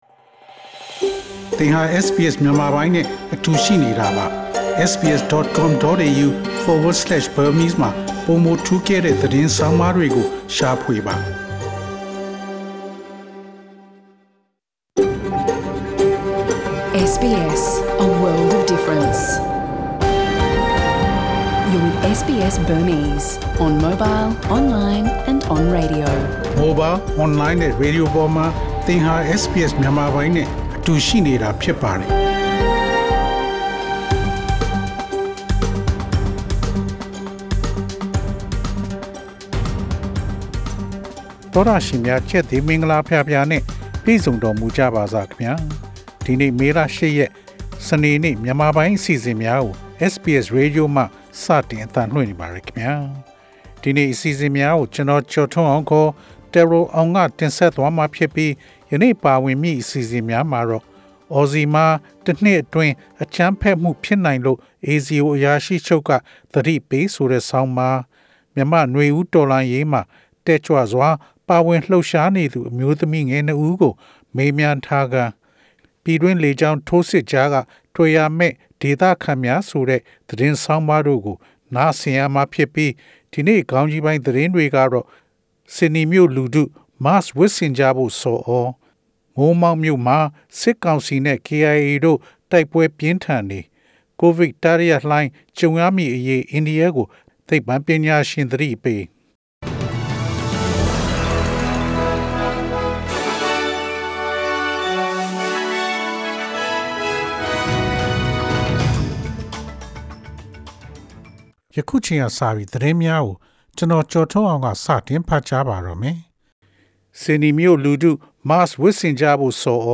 SBS မြန်မာပိုင်း အစီအစဉ်ပေါ့ကတ်စ် သတင်းများ။